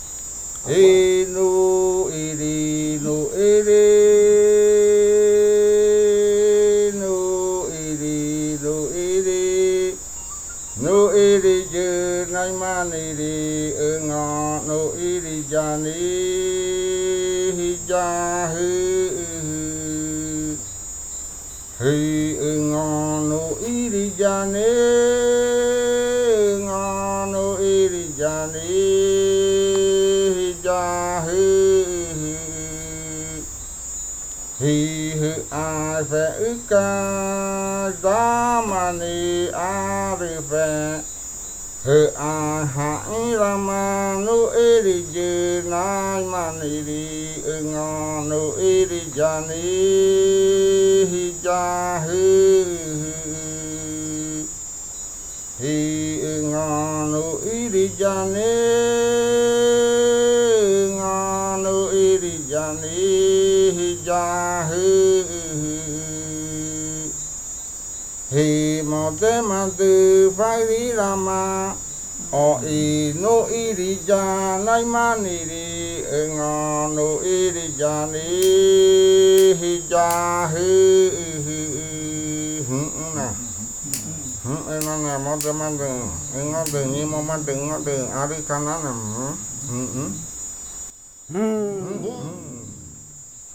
Leticia, Amazonas, (Colombia)
Grupo de danza Kaɨ Komuiya Uai
El primer fakariya de la variante jimokɨ (cantos de guerrero).
The first fakariya chant of the Jimokɨ variant (Warrior chants).
Flautas de Pan y cantos de fakariya del grupo Kaɨ Komuiya Uai